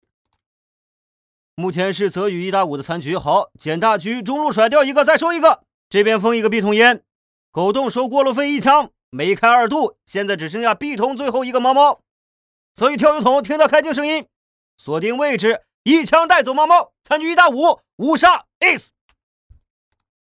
CS游戏解说